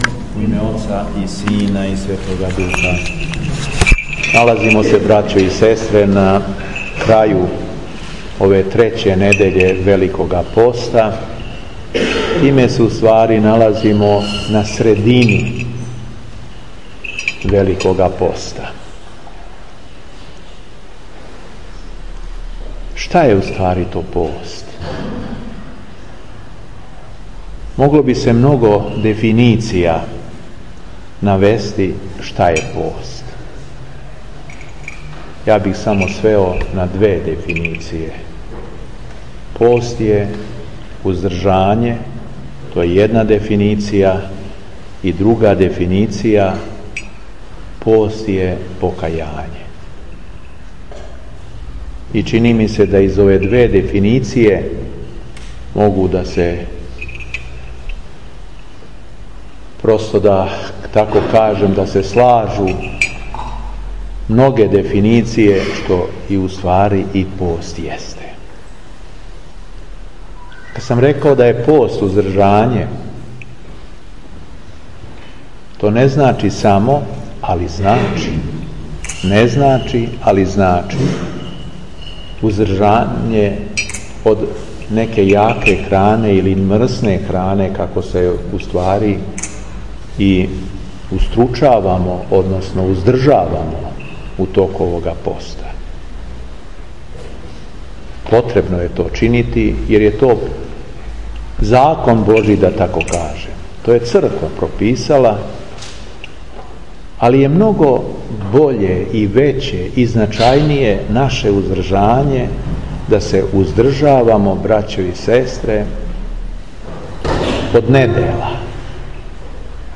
Дана 17. марта 2018. године, када наша Света Црква молитвено прославља светог Герасима Јорданског, Његово Преосвештенство Господин Јован Епископ шумадијски служио је Свету Архијерејску Литургију у храму Свете Петке у Смедеревској Паланци.
Беседа Епископа шумадијског Г. Јована